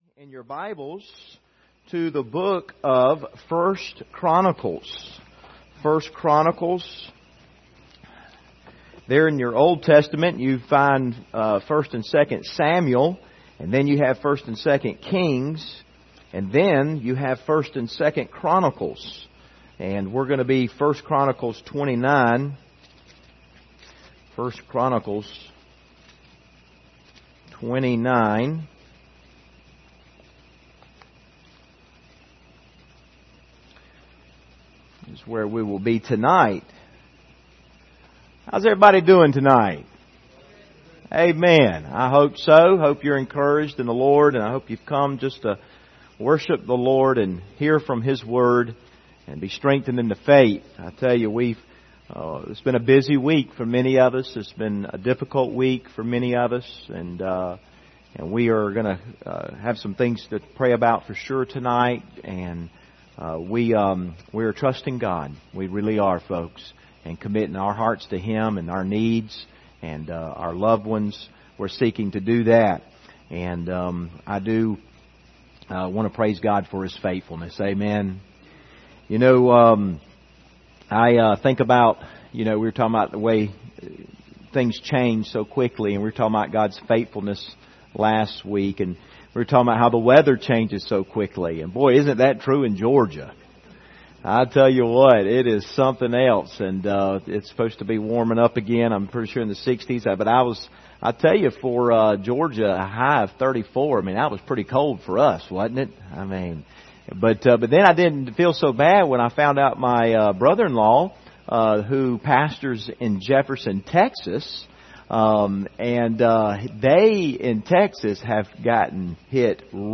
Service Type: Wednesday Evening Topics: God's Power